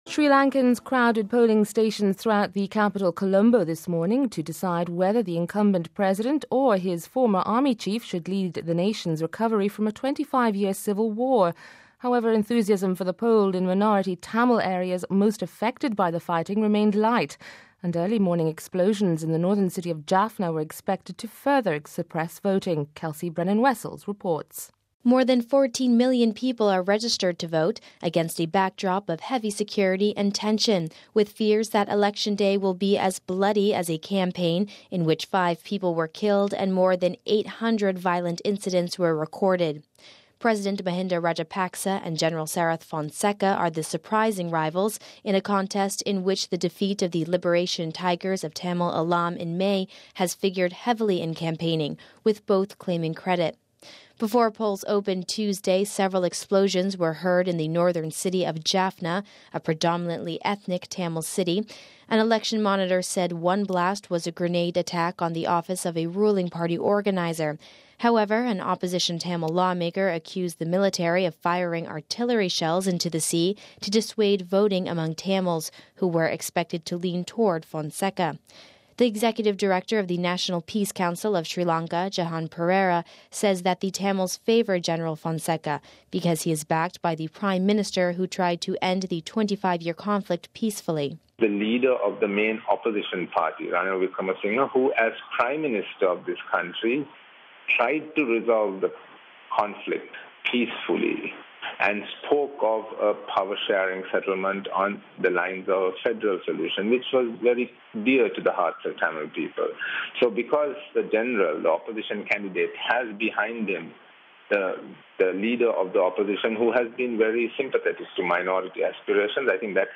We have this report: RealAudio